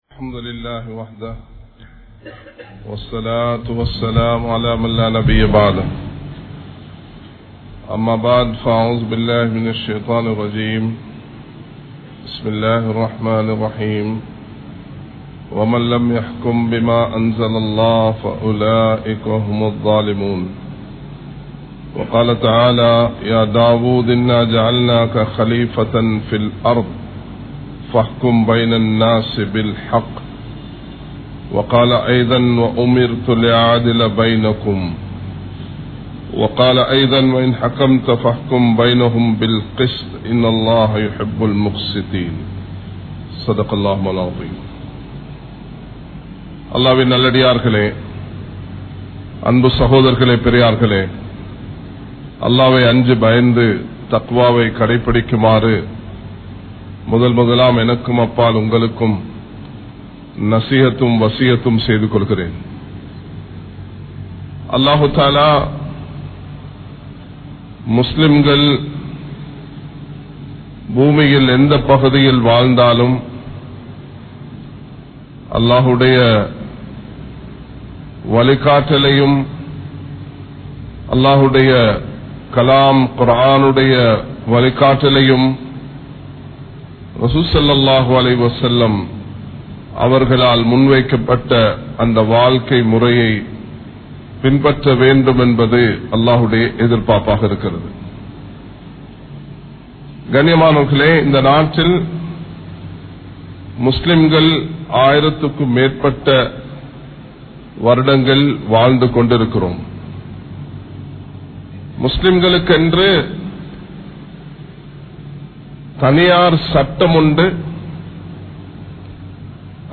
Muslim Thaniyaar Sattamum Thatpoathaya Nilamaium (முஸ்லிம் தனியார் சட்டமும் தற்போதைய நிலமையும்) | Audio Bayans | All Ceylon Muslim Youth Community | Addalaichenai
Kollupitty Jumua Masjith